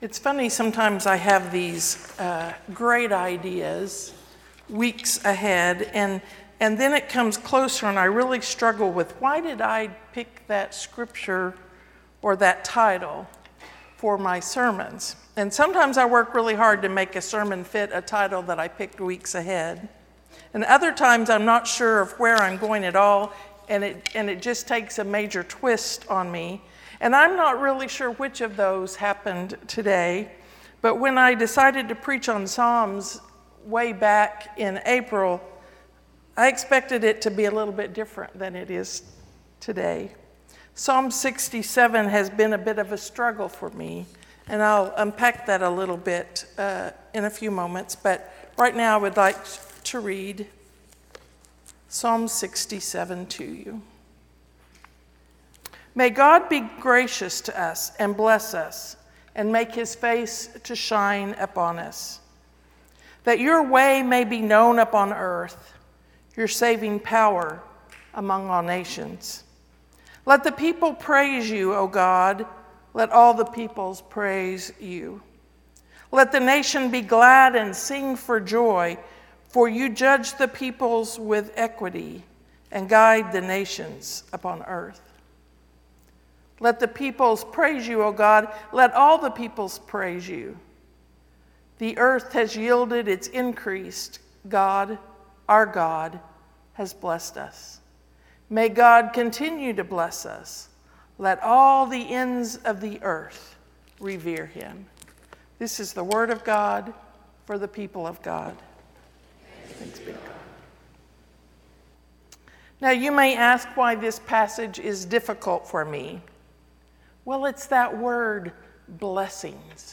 How Big Is Your God? (Sermon) - The Church @ Highland Park
how_big_is_your_god_sermon_5_26_19.mp3